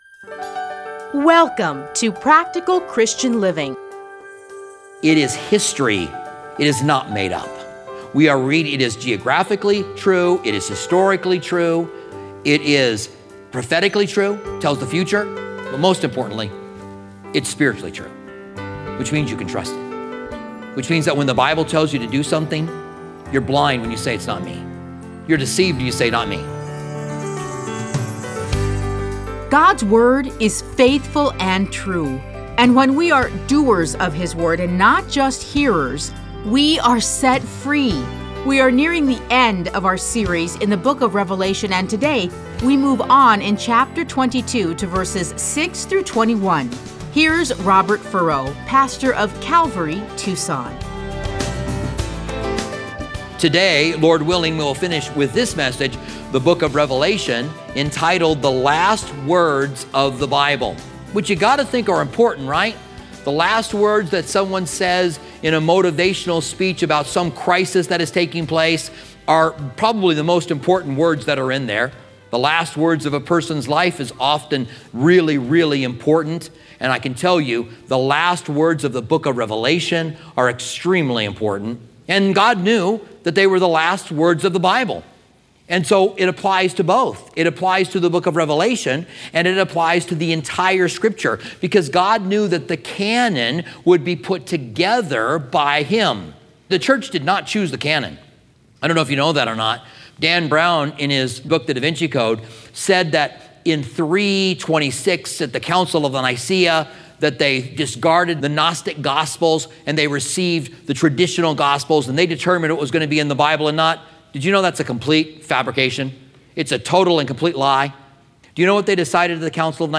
Listen to a teaching from Revelation 22:6-21.